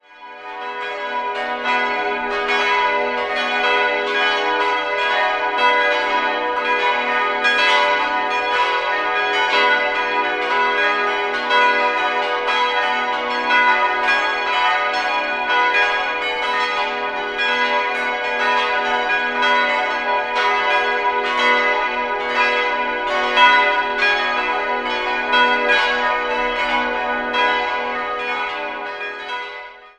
5-stimmiges Geläute: h'-cis''-dis''-fis''-gis''
Die cis''-Glocke wurde 1842 von Konrad Braun in Nürnberg gegossen, alle anderen stammen aus dem Jahr 2012 von der Gießerei Bachert in Karlsruhe.
Heutiges Geläut (seit 2012)